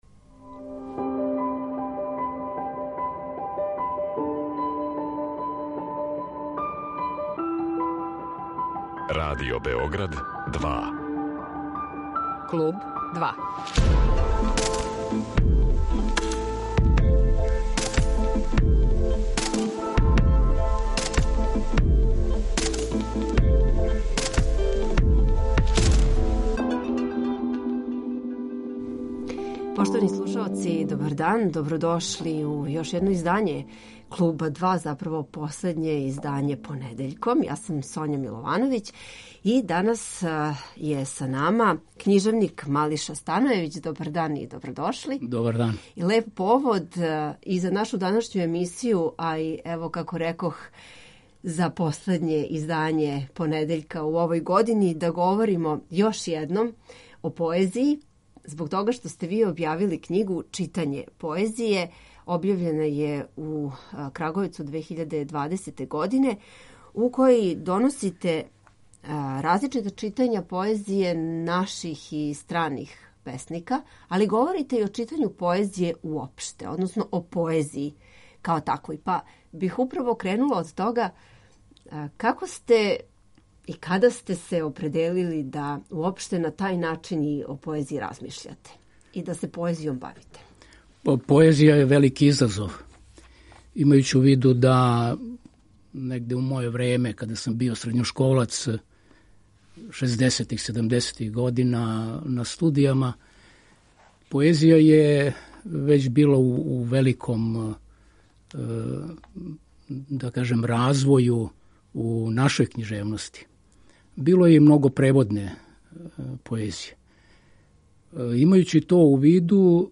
То нас уверава и у значај постојања „поезије уживо", о чему ћемо, између осталог, разговарати са нашим данашњим гостом.